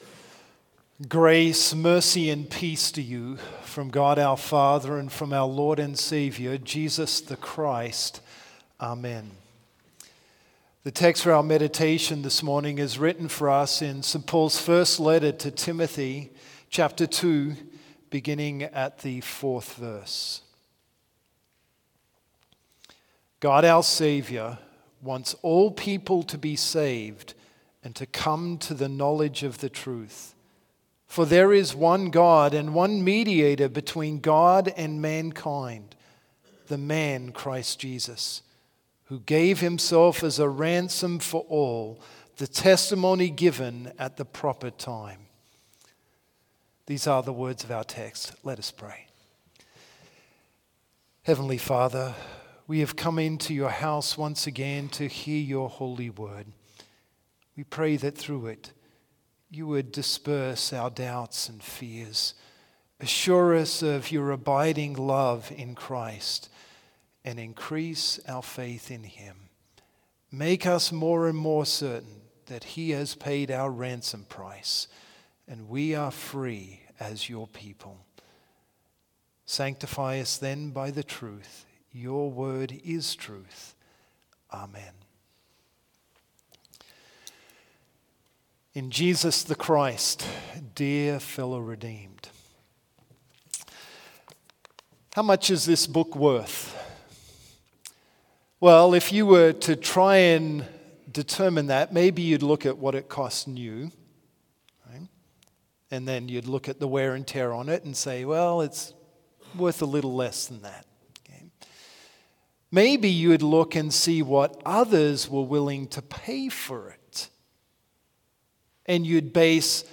Complete service audio for Chapel - Thursday, January 9, 2025